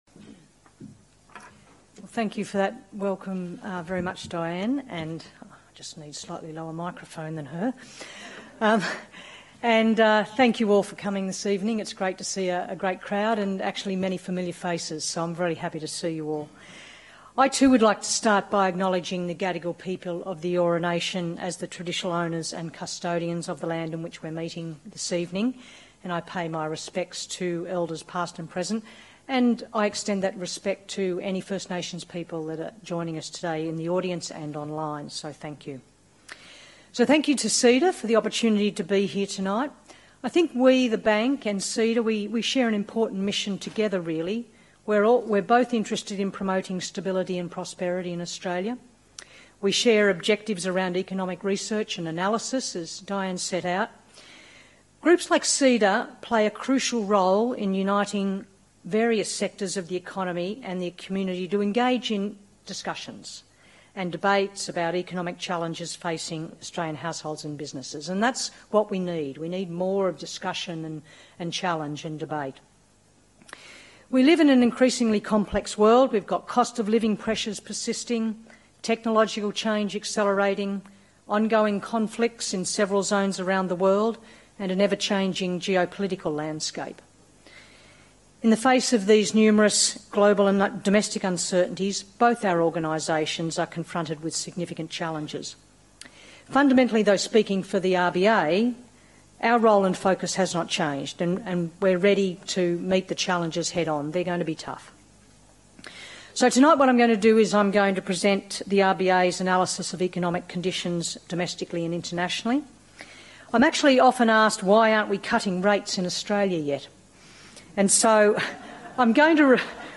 Speech delivered by Michele Bullock, Governor, at the Committee for Economic Development of Australia (CEDA) Annual Dinner, Sydney